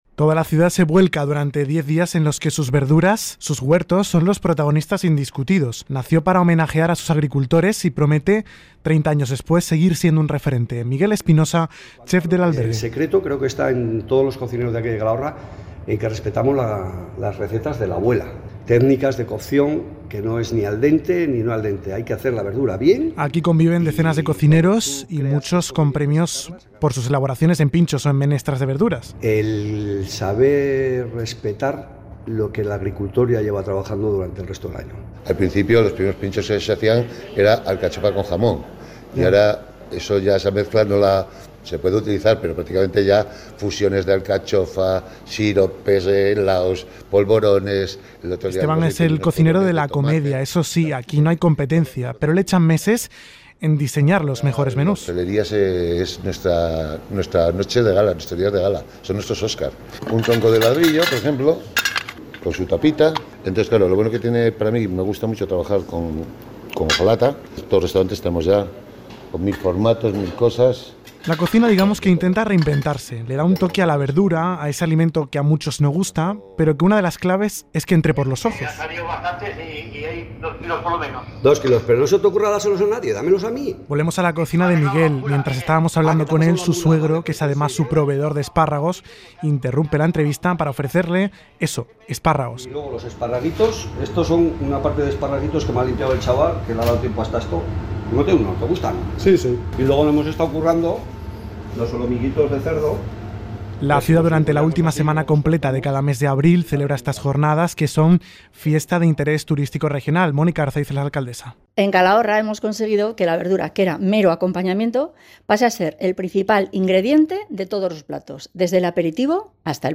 Reportaje | Calahorra: la ciudad de las verduras – Podium Podcast